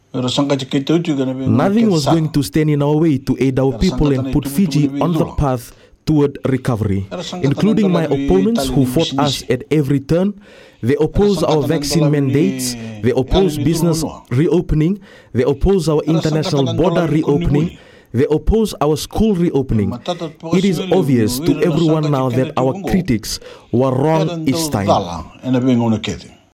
Speaking on the iTaukei Affairs Na iLalakai program on Radio Fiji One, Prime Minister Voreqe Bainimarama says the government negotiated highly concessional loans at zero percent to point zero one percent interest rates from reputable financial institutions such as the World Bank.